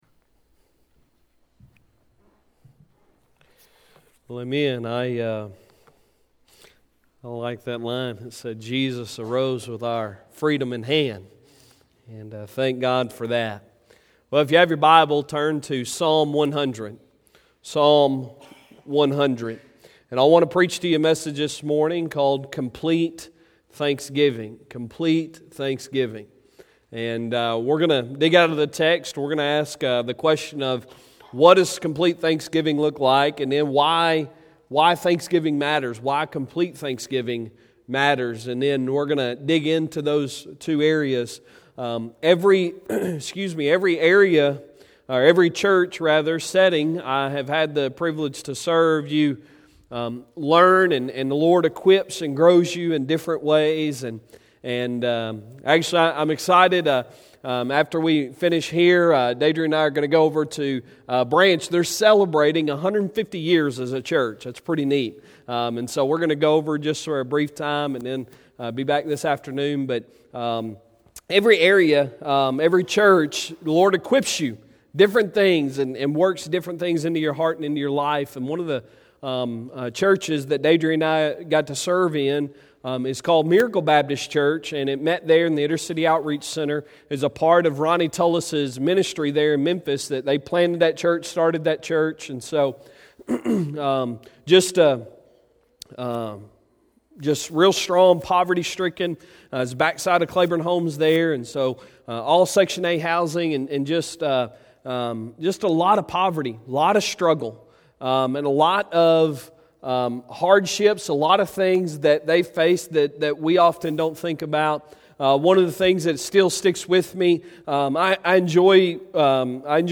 Sunday Sermon November 17, 2019